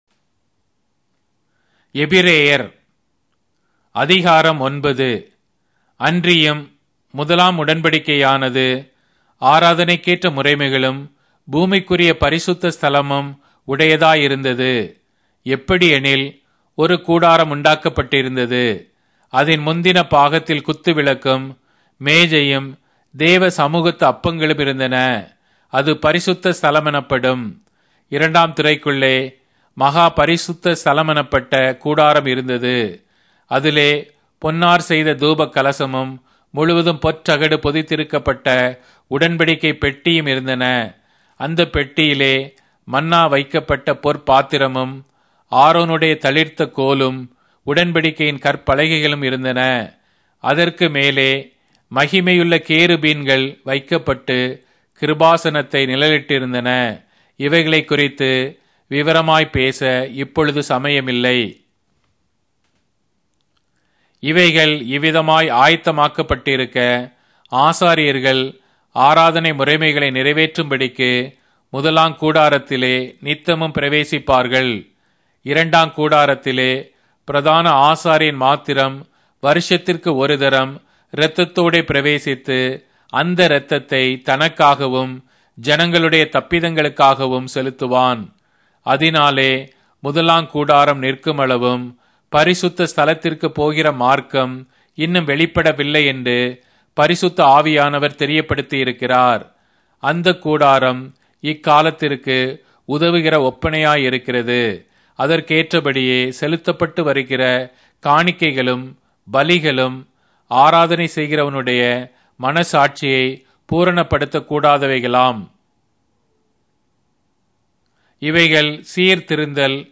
Tamil Audio Bible - Hebrews 11 in Esv bible version